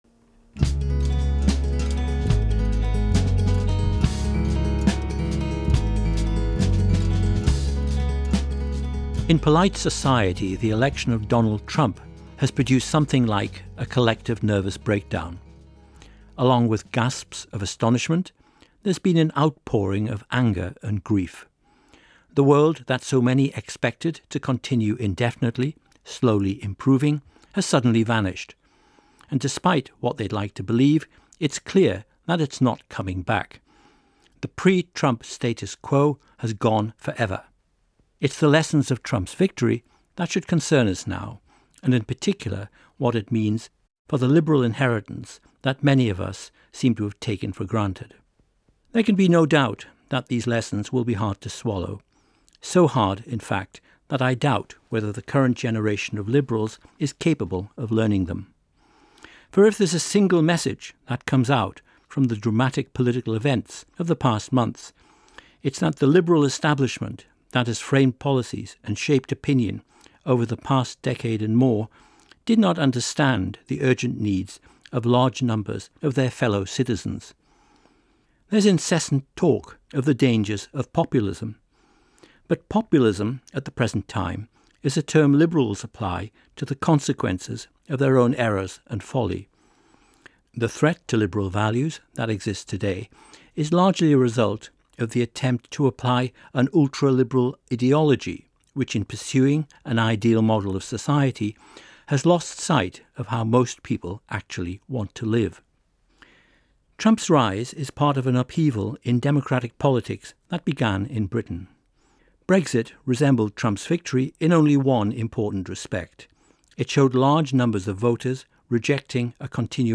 After the Brexit vote I was grateful to a number of thinkers who had contributed to a week of short talks on BBC radio.
It was – as with Brexit – left to Professor John Gray to present a hard truth.